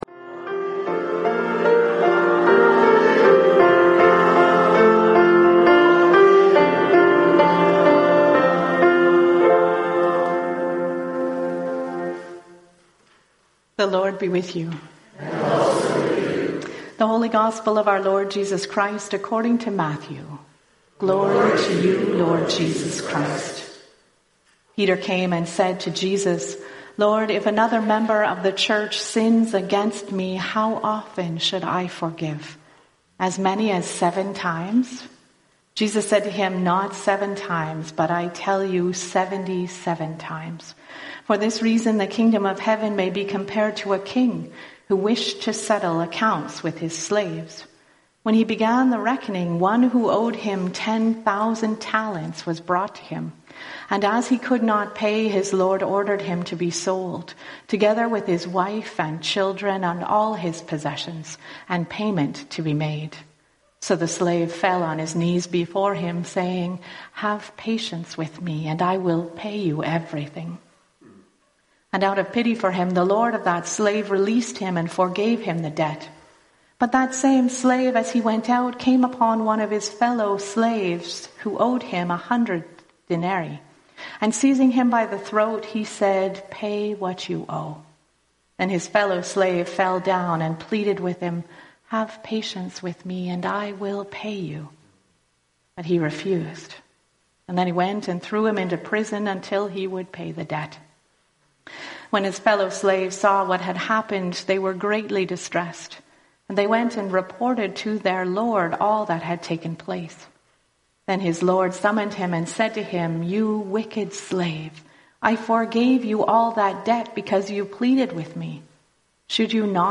Sermon Audio Recordings | St. Andrew Anglican Church